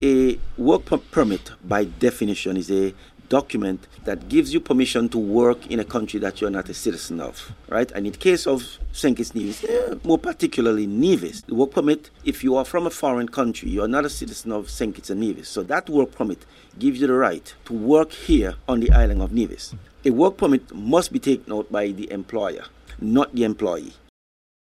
Mr. Daniel took the time to explain the importance of the permit: